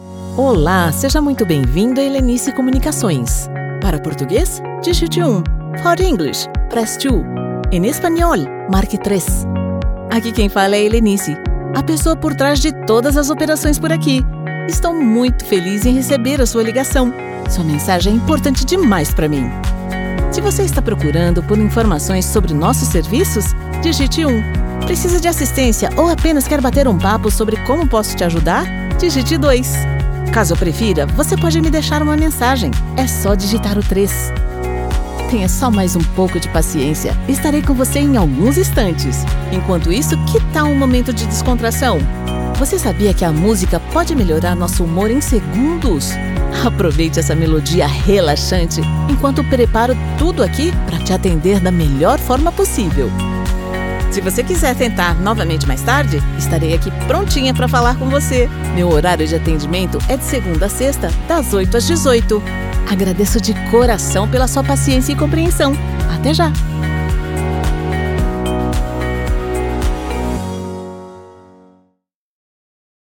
Vriendelijk, Natuurlijk, Vertrouwd, Veelzijdig, Zakelijk
Telefonie